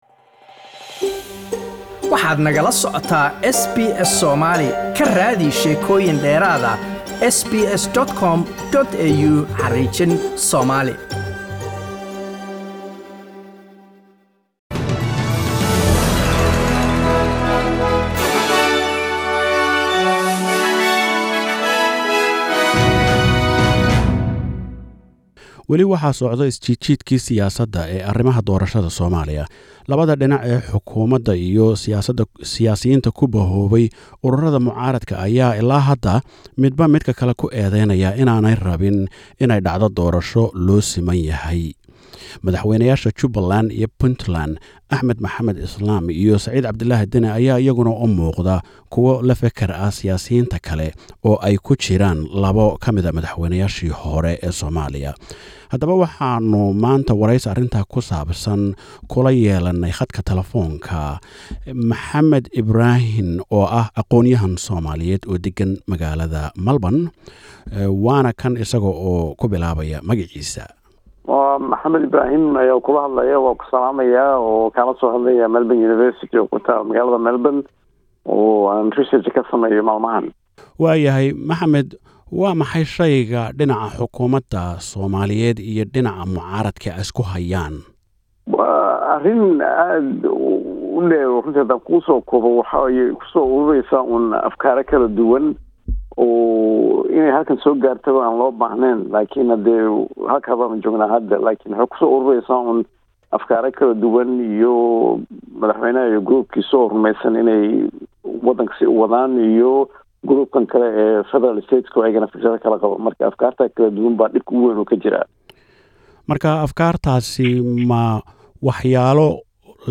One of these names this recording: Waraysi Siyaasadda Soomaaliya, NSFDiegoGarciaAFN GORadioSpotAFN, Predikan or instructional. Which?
Waraysi Siyaasadda Soomaaliya